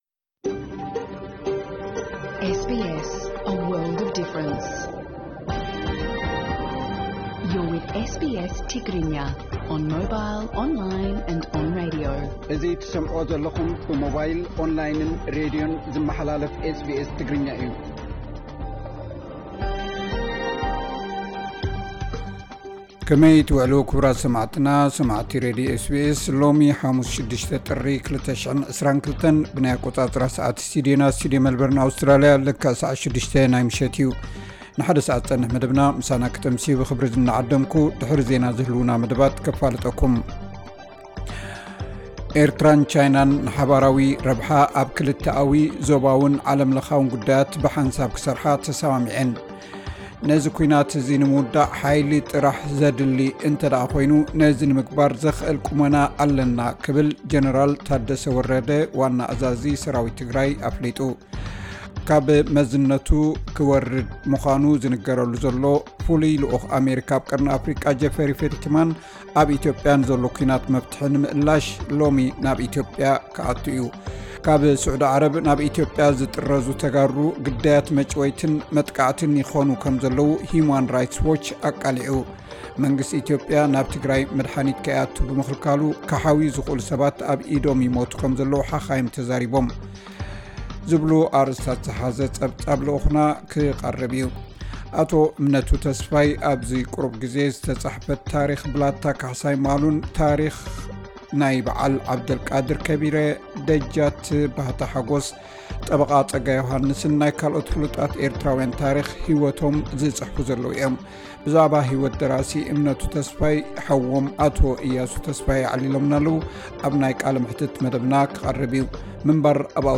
ዕለታዊ ዜና SBS ትግርኛ (06 ጥሪ 2022)